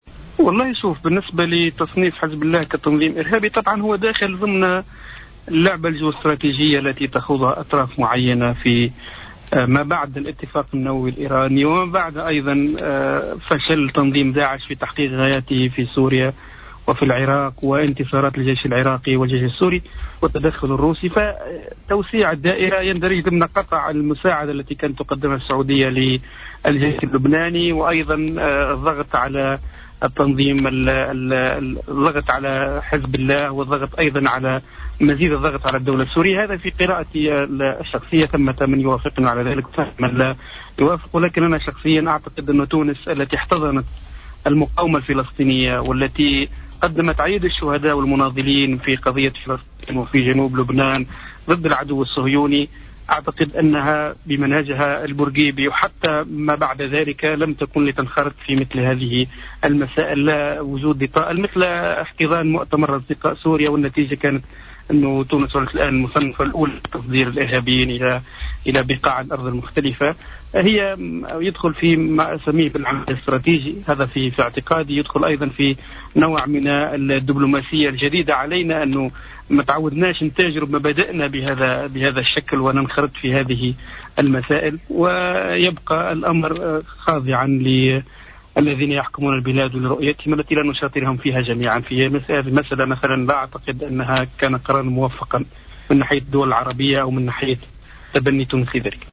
حوار هاتفي مع الجوهرة أف أم